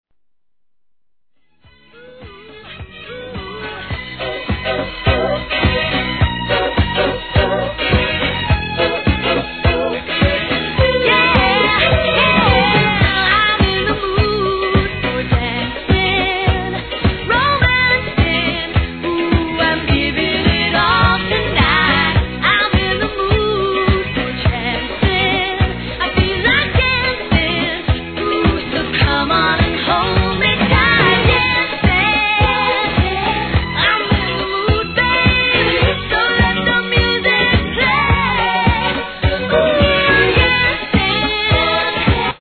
HIP HOP/R&B
大好評！！オトナなパーティーを彩るダンクラ、フリーソウルがギッシリ！！